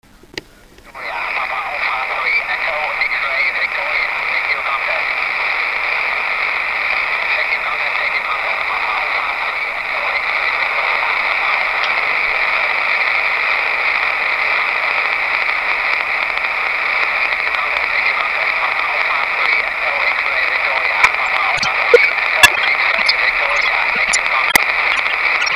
Pásmo: 432 MHz
FT817 a PA U100 firmy GAGA ( 50W out)
Ze zvukového záznamu ( plný šumu ) je vidět jak to nefungovalo.